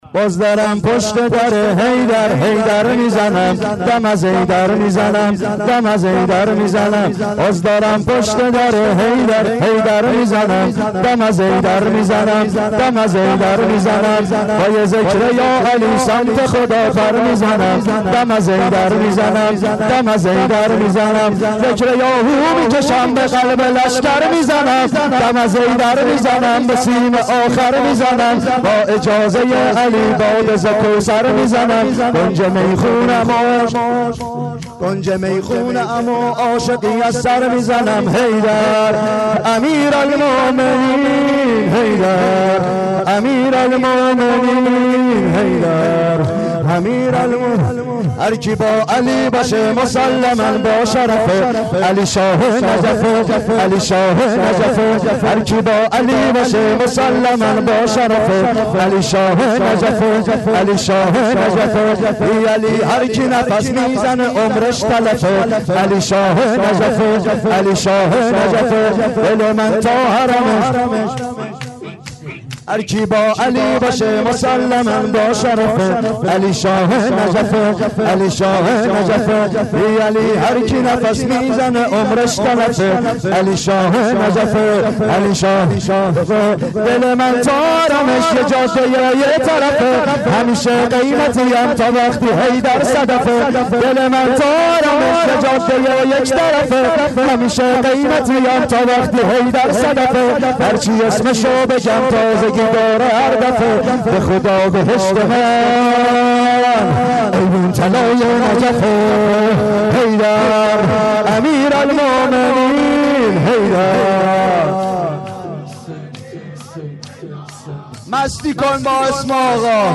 گلچین شورهای محرم 93
شور شب هفتم : باز دارم پشت دره حیدر هی در میزنم